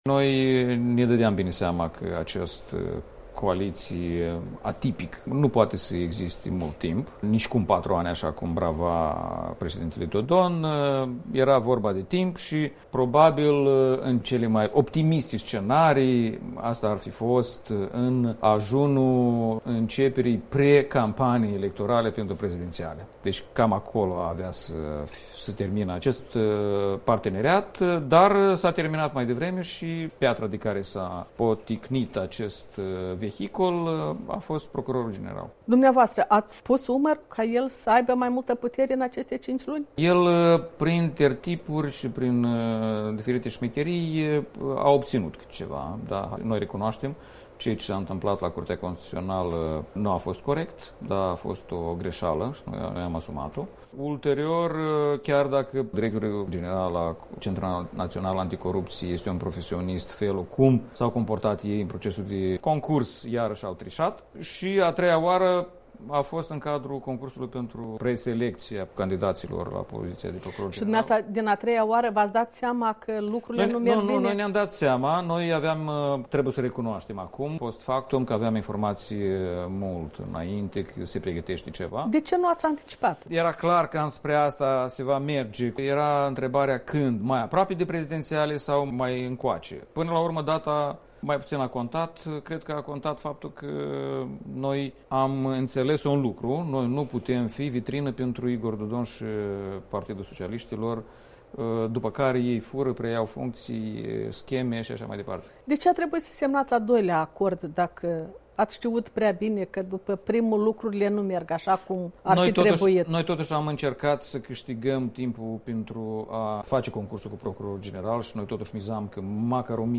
Interviu cu Igor Grosu